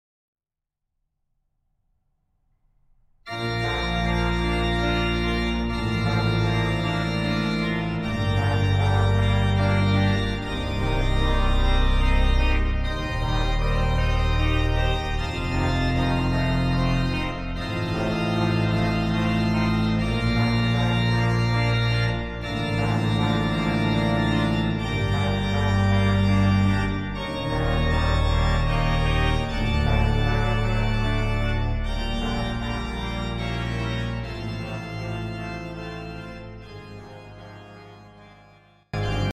L’organo-orchestra Lingiardi 1877 di S. Pietro al Po in Cremona